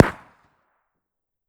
CookoffSounds / shotbullet / mid_2.wav
Cookoff - Improve ammo detonation sounds